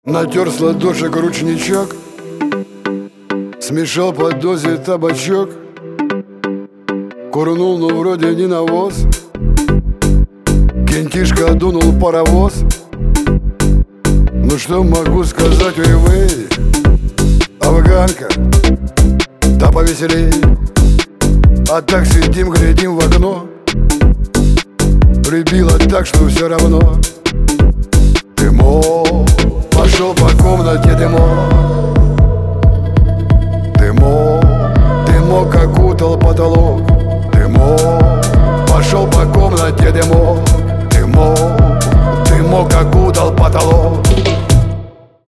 веселые
русский шансон
танцевальные
бит
хаус
шансон-хаус